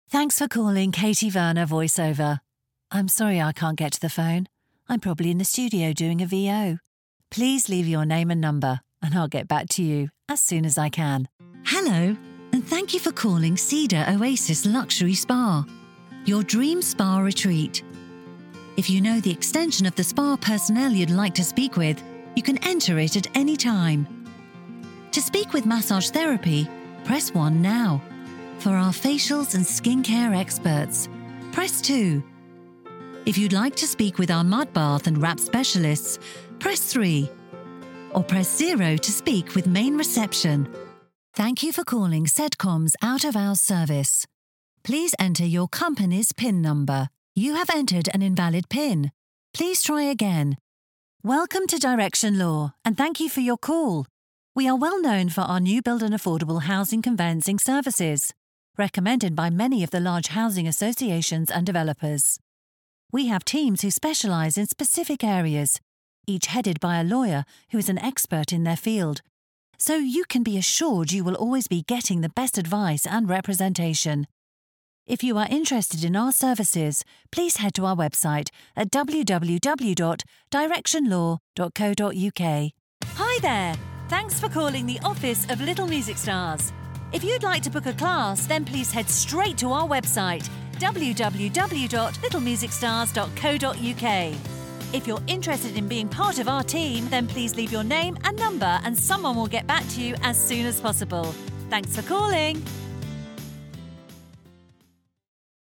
Female
British English (Native)
Approachable, Bubbly, Confident, Conversational, Friendly, Natural, Smooth, Upbeat, Witty
Microphone: Rode NT1A